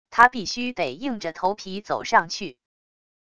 他必须得硬着头皮走上去wav音频生成系统WAV Audio Player